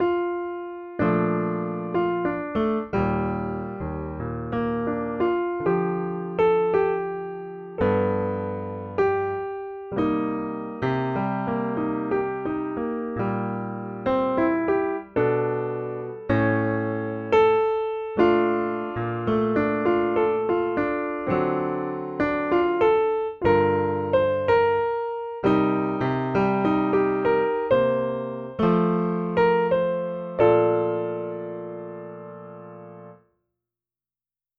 (Please ignore the obvious tempo issues…still very much a newbie overall.)